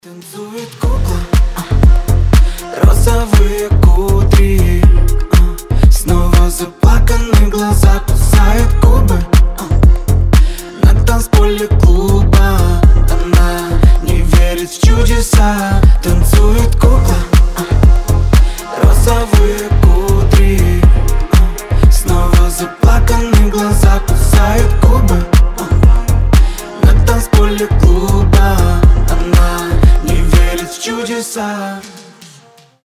• Качество: 320, Stereo
ритмичные
грустные
дуэт